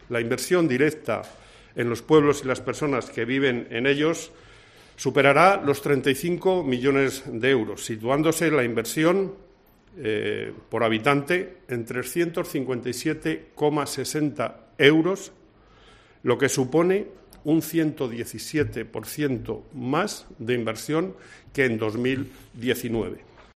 Miguel Ángel de Vicente, presidente de la Diputación Provincial, sobre los municipios pequeños